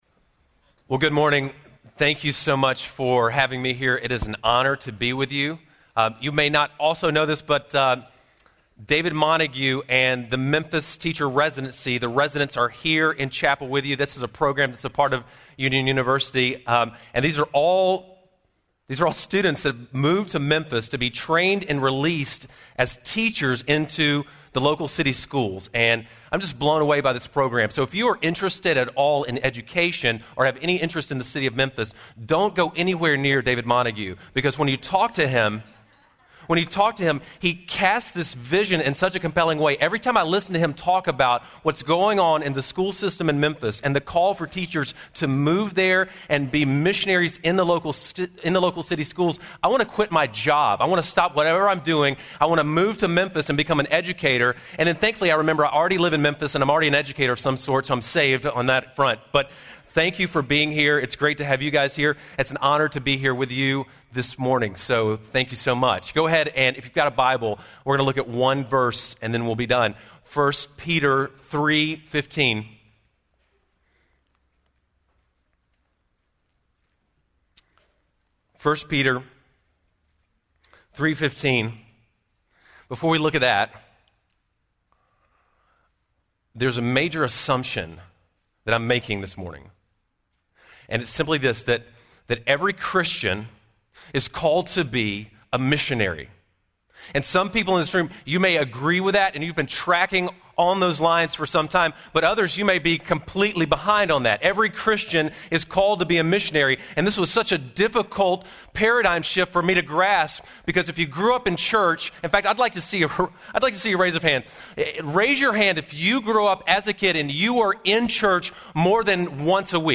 Chapel
Address: "A Jesus-Shaped Mission" from 1 Peter 3:15-16 & John 8:1-11 Recording Date